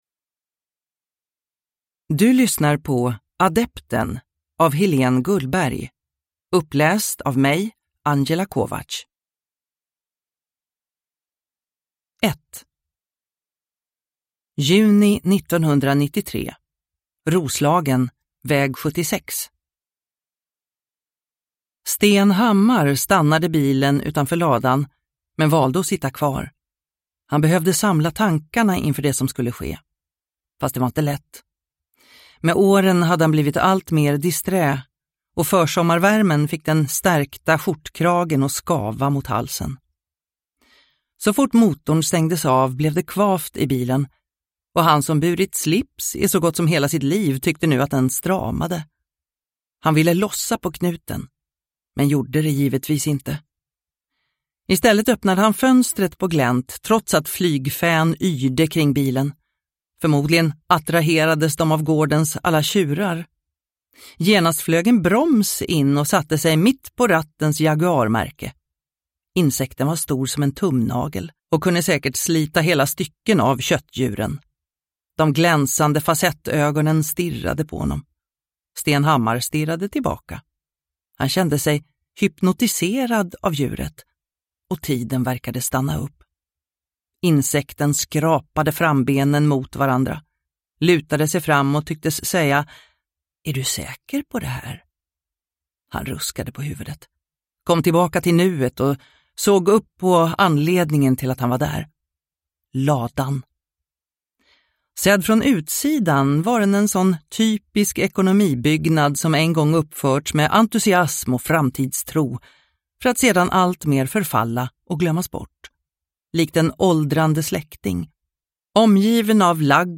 Adepten – Ljudbok
Deckare & spänning Njut av en bra bok